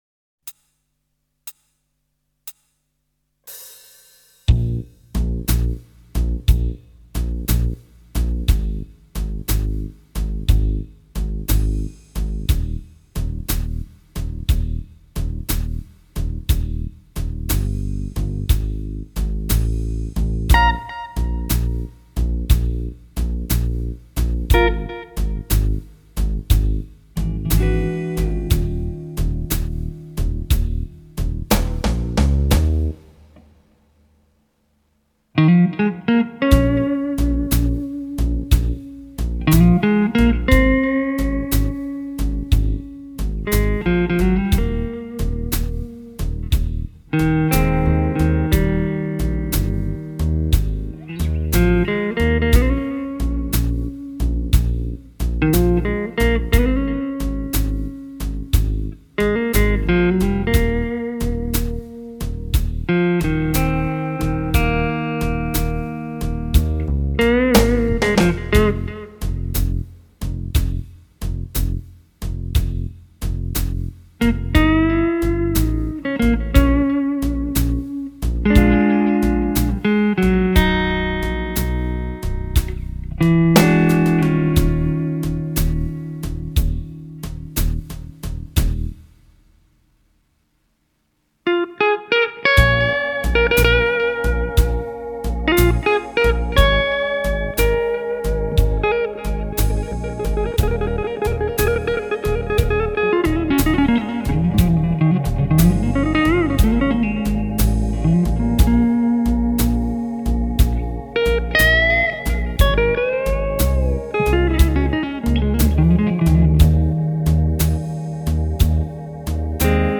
Cordes neuves et identiques sur les 2 guitares (meme marque, meme
vibrato sur la Strat vu que la Variax 500 n'en possede pas.
Brush-Variax.mp3